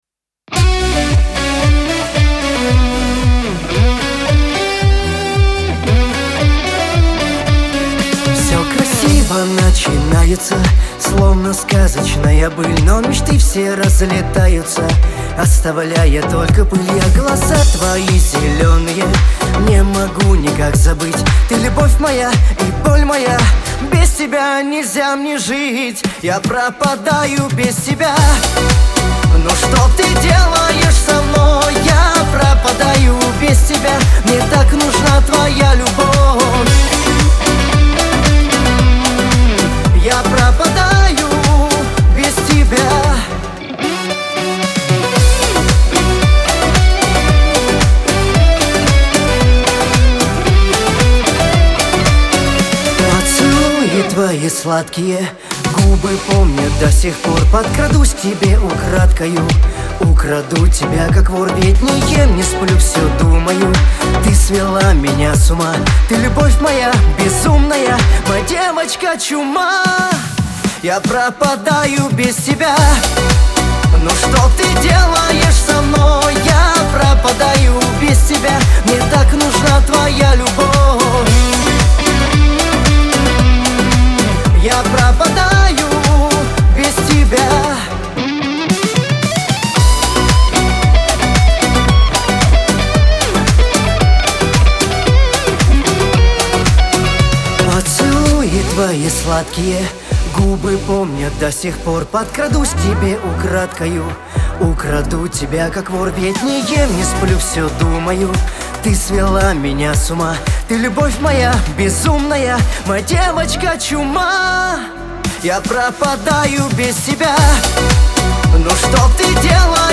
диско , эстрада